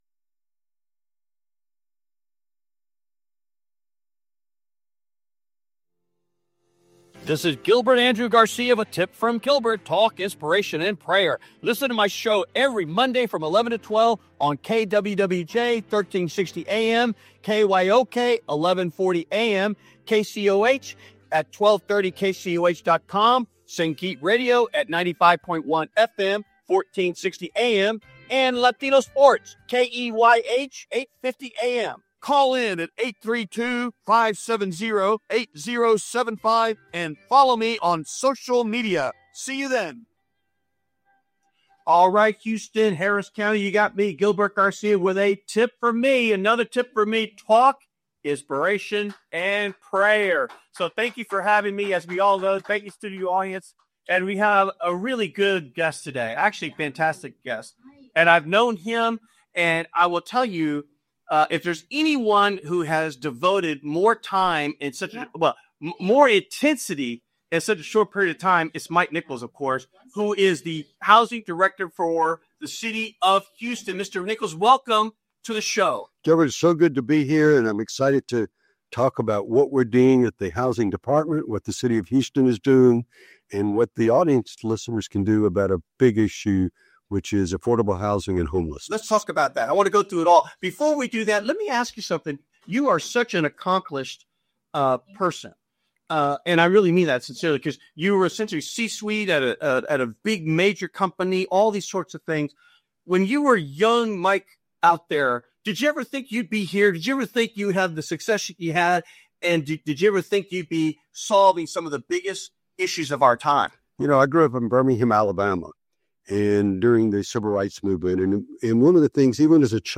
Talk, Inspiration & Prayer
LIVE Every Monday from 11:00am to 12:00pm CT on Facebook Live